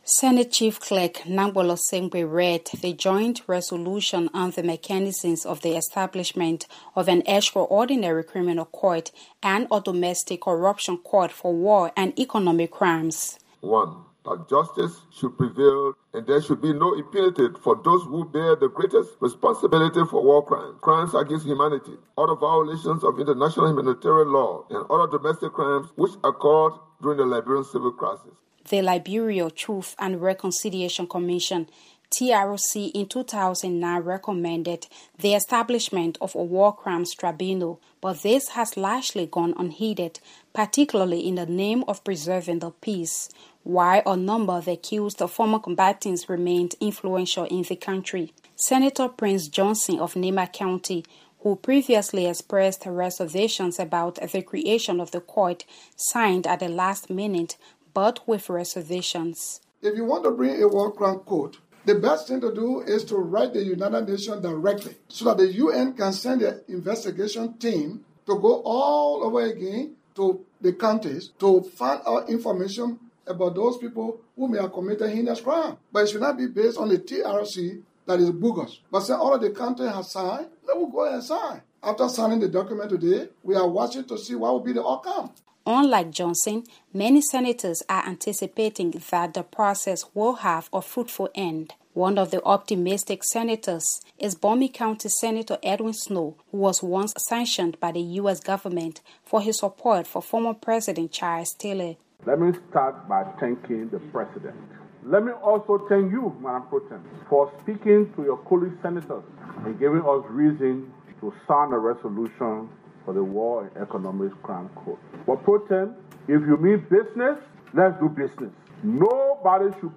has details from Monrovia